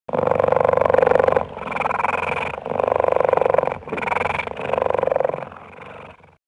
На этой странице собраны звуки гепарда в естественной среде обитания: от грозного рыка до нежного мурлыканья детенышей.
Гепард тихо мурлычет